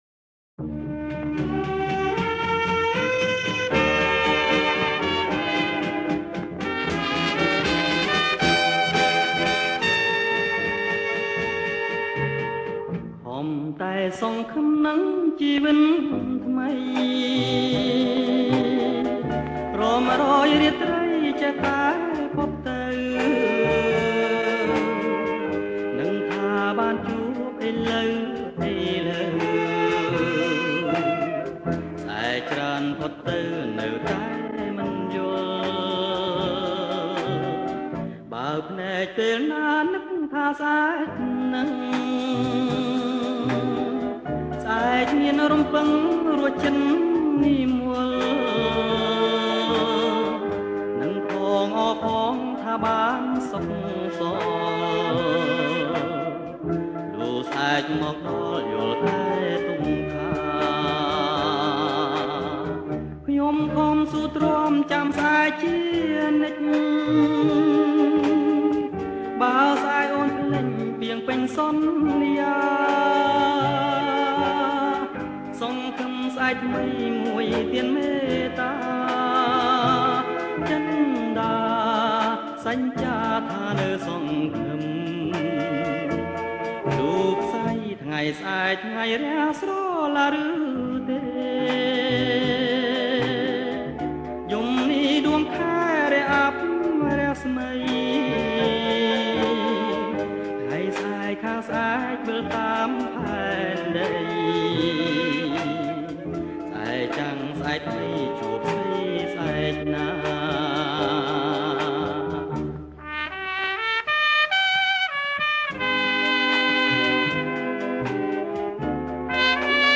• ប្រគំជាចង្វាក់ Slow Rock